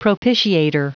Prononciation du mot propitiator en anglais (fichier audio)
Prononciation du mot : propitiator